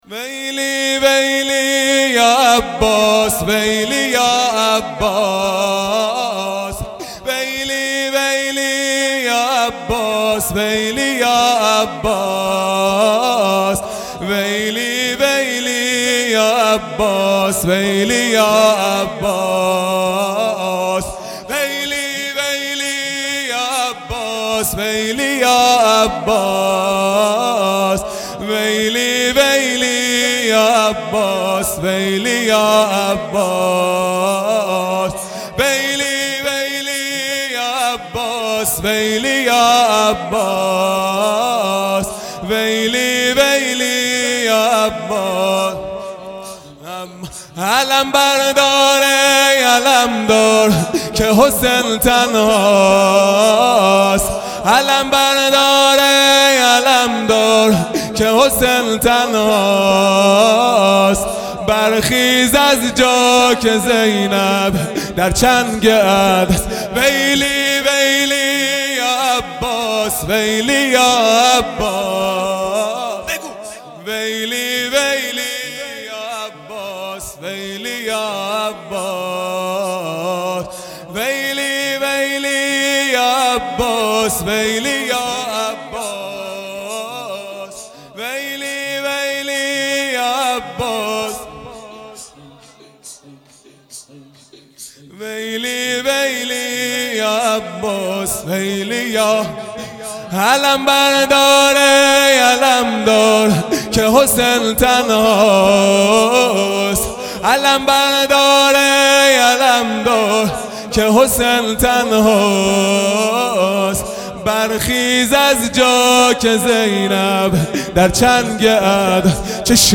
شور | ویلی یا عباس | چهارشنبه ۲۷ مرداد ۱۴۰۰
دهه اول محرم الحرام ۱۴۴۳ | صبح تاسوعا | چهارشنبه ۲7 مرداد ۱۴۰۰